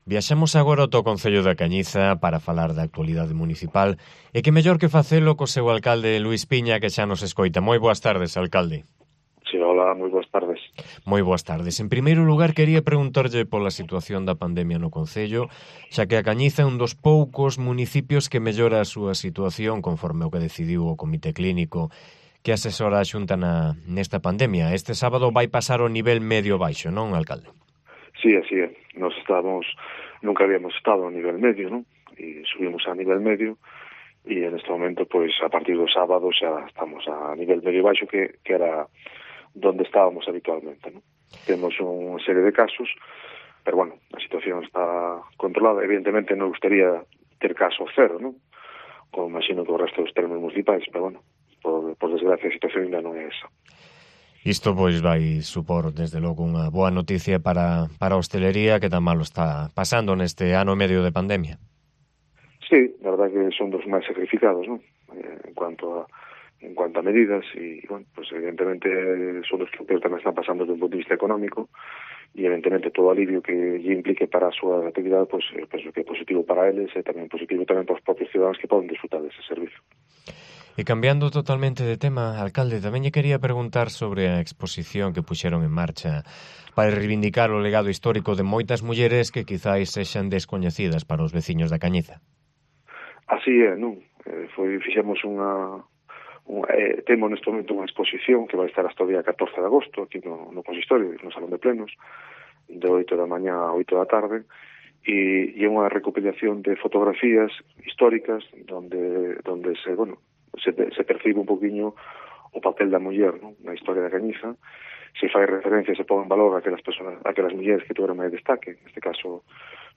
Entrevista a Luis Piña, alcalde de A Cañiza